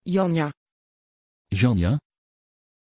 Pronunciation of Janja
pronunciation-janja-nl.mp3